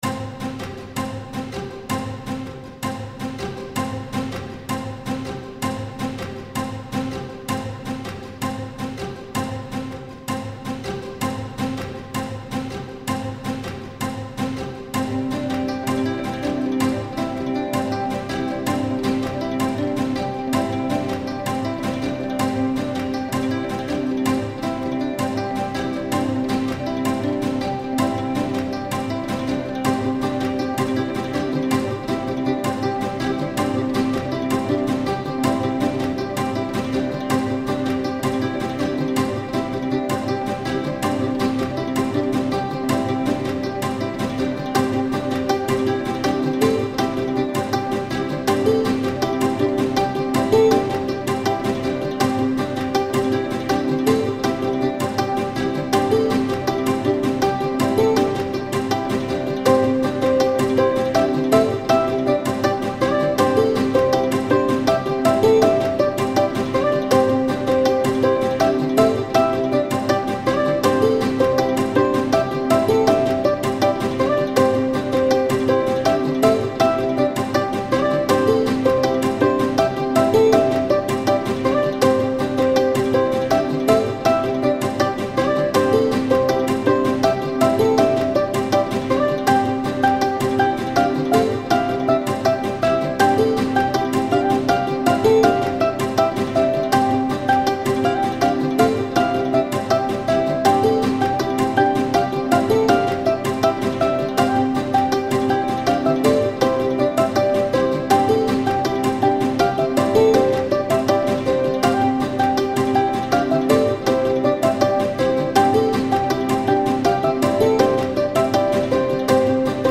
joie - ensemble instruments